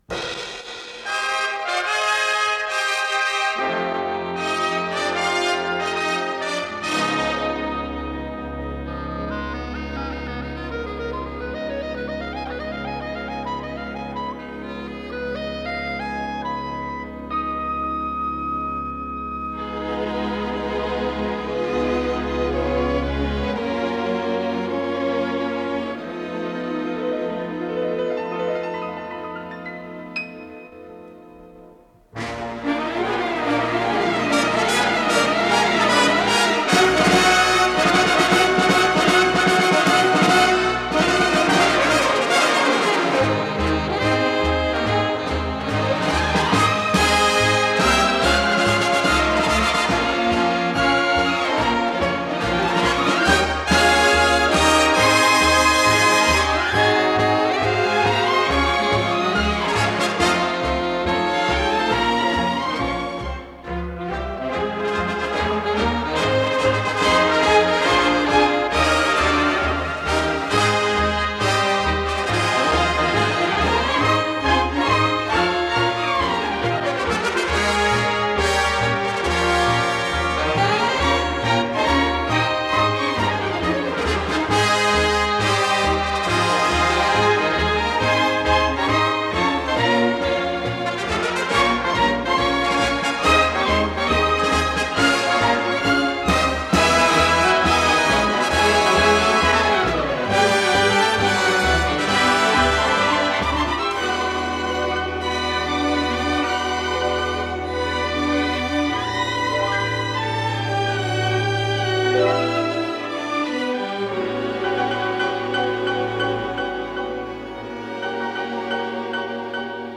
Исполнитель: Оркестр